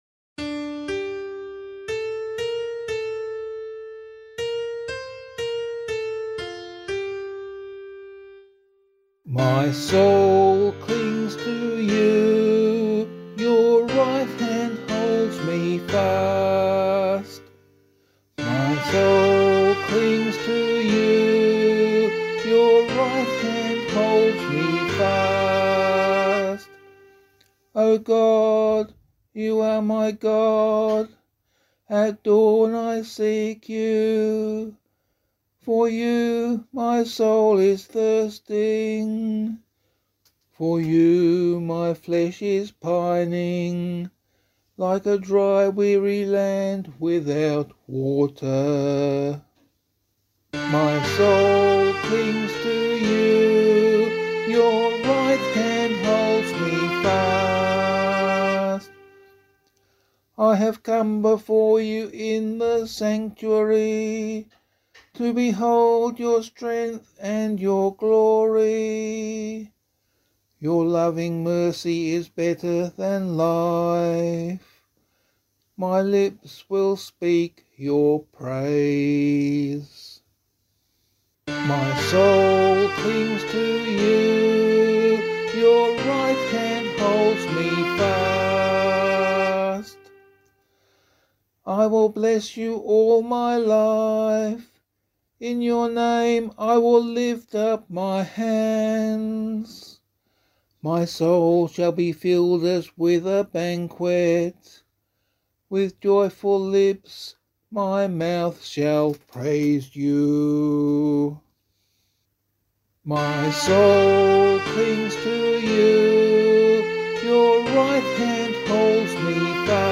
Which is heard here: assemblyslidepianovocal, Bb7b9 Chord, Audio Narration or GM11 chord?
assemblyslidepianovocal